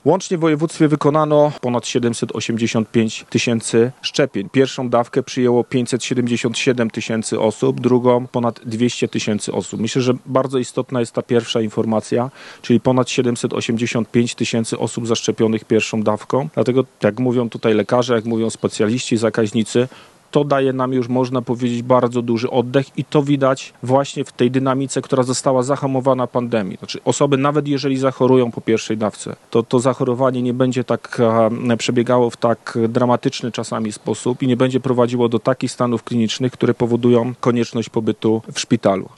W Szczecinie odbyła się konferencja wojewody zachodniopomorskiego na temat powrotu do szerokiego leczenia szpitalnego.